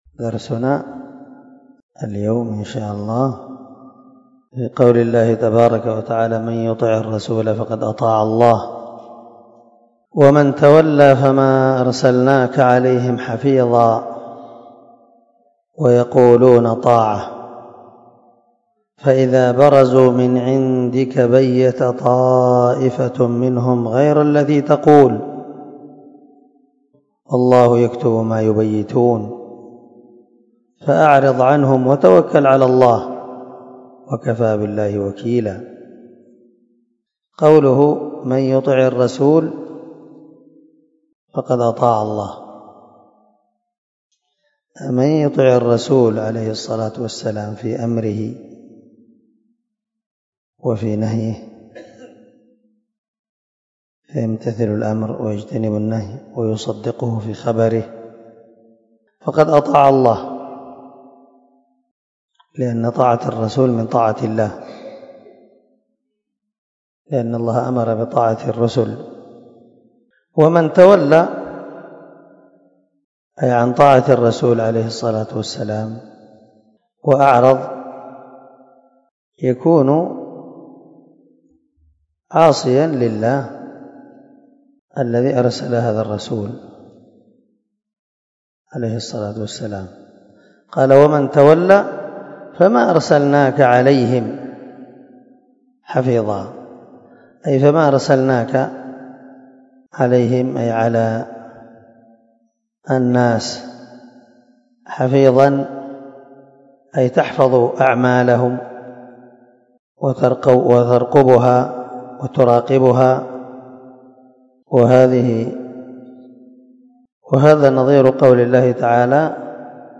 285الدرس 53 تفسير آية ( 80 – 81 ) من سورة النساء من تفسير القران الكريم مع قراءة لتفسير السعدي
دار الحديث- المَحاوِلة- الصبيحة.